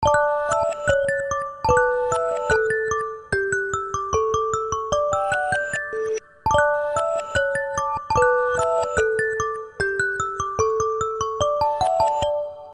Рингтоны без слов
Мелодичные , Инструментальные